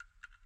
File:ClockTick2.ogg